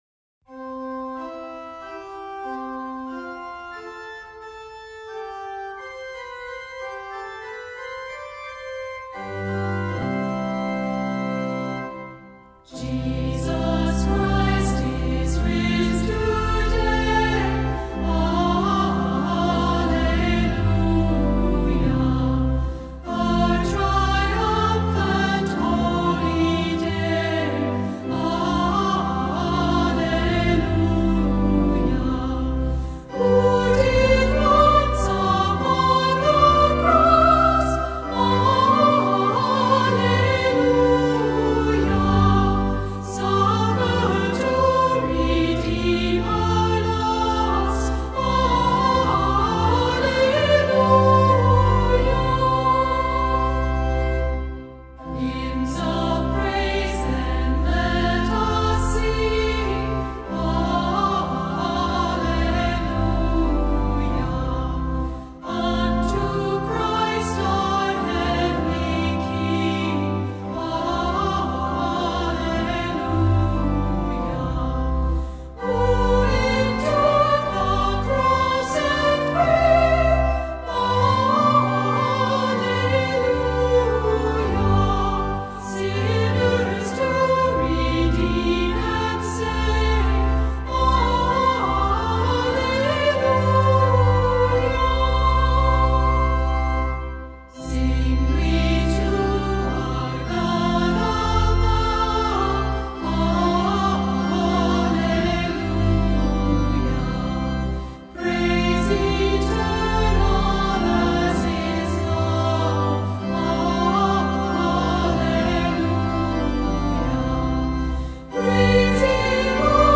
Welcome to this time of worship.